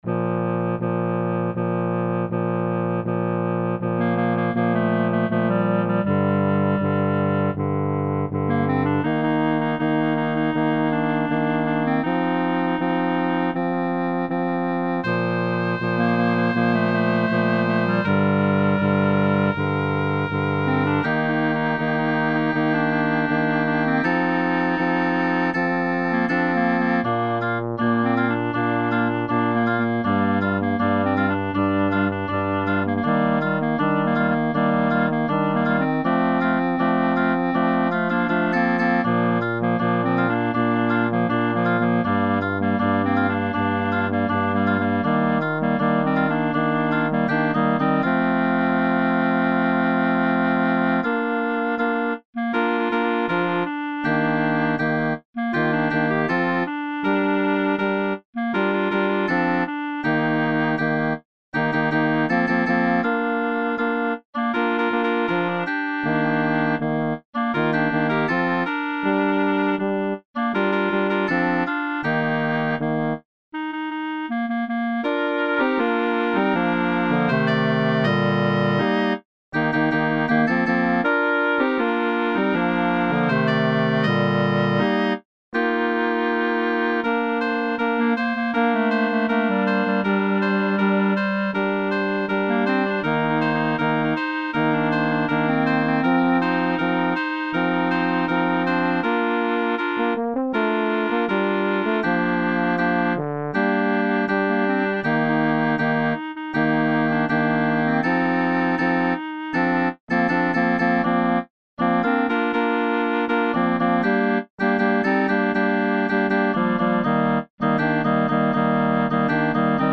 Upper Voices Learning Track Sample